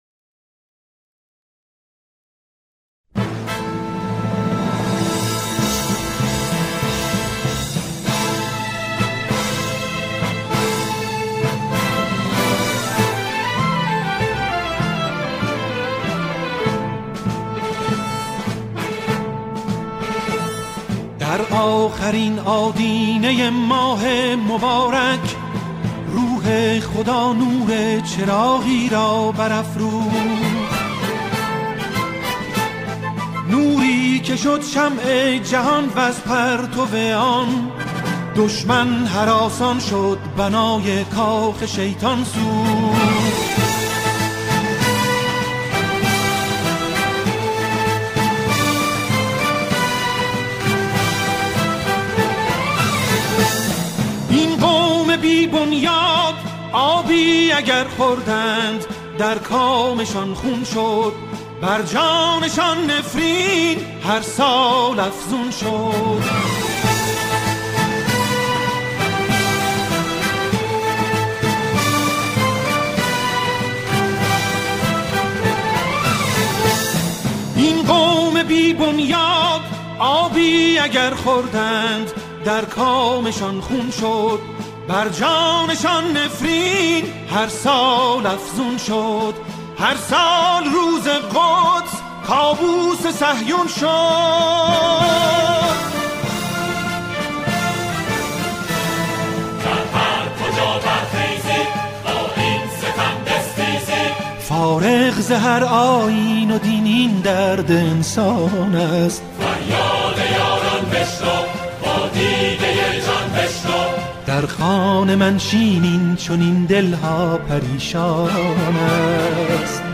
سرودهای فلسطین